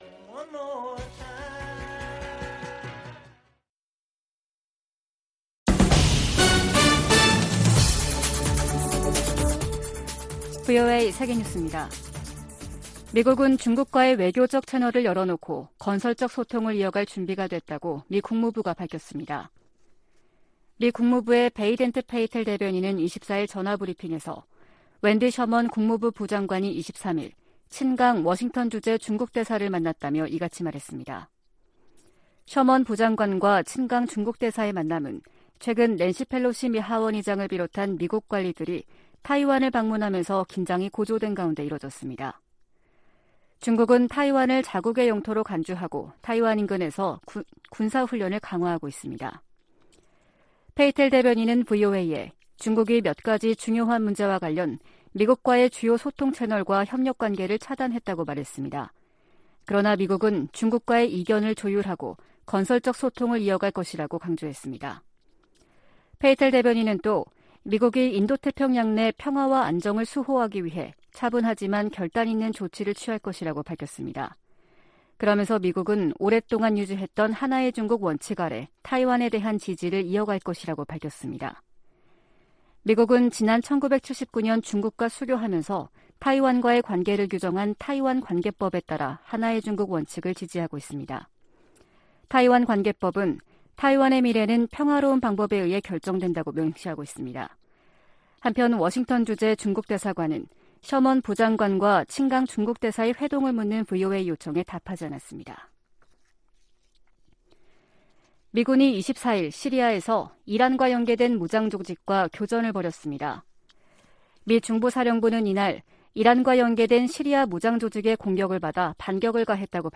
VOA 한국어 아침 뉴스 프로그램 '워싱턴 뉴스 광장' 2022년 8월 26일 방송입니다. 일각에서 거론되는 ‘미북 관계 정상화’ 방안과 관련해 미국 정부는 ‘동맹과의 긴밀한 협력’이 중요하다고 밝혔습니다. 주한미군의 사드는 한국을 보호하기 위한 방어체계라고 미 국방부가 강조했습니다. 약 두 달 앞으로 다가온 미국 중간선거에서, 한반도 문제에 적극 개입해 온 주요 의원들이 재선될 것으로 관측됩니다.